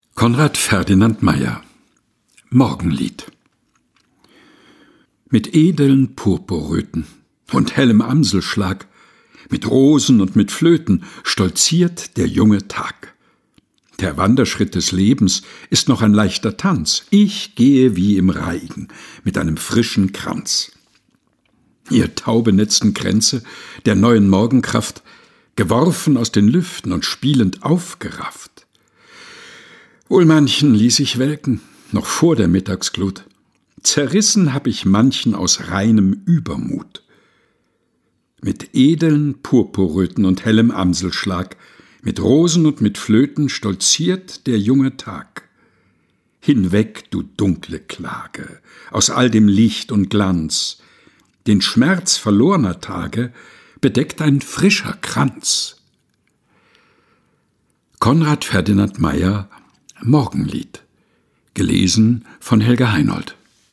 Ohrenweide ist der tägliche Podcast mit Geschichten, Gebeten und Gedichten zum Mutmachen und Nachdenken - ausgesucht und im Dachkammerstudio vorgelesen